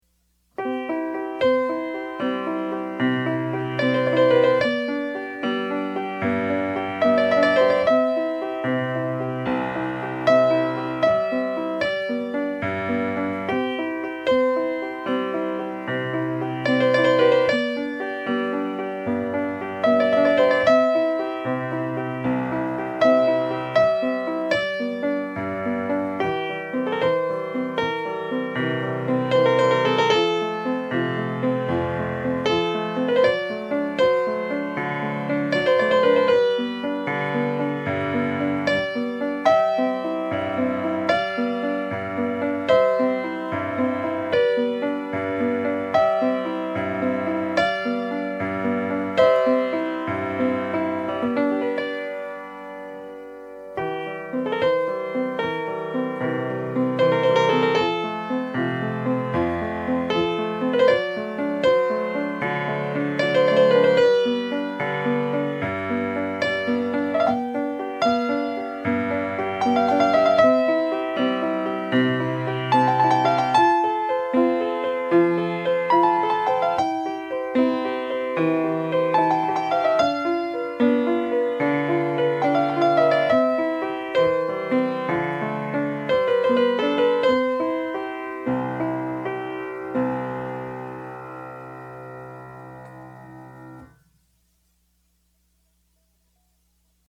DIGITAL SHEET MUSIC - PIANO SOLO